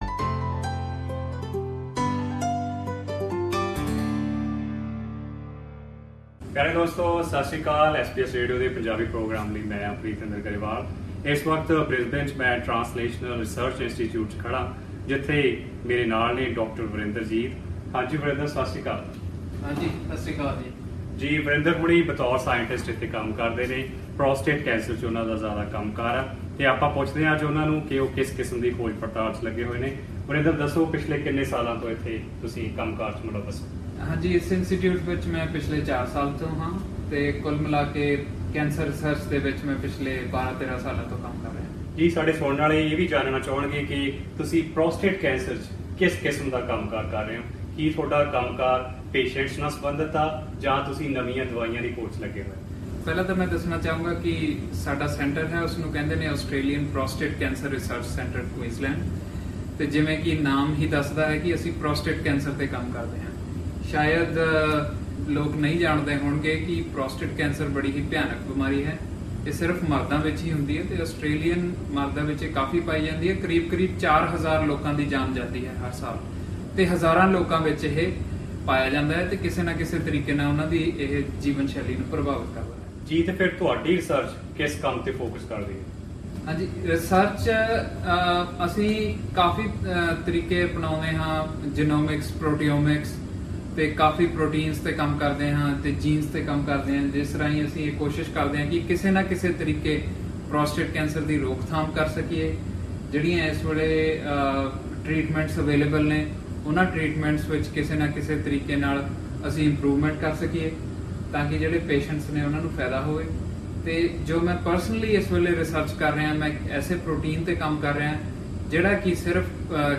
Here we have a short conversation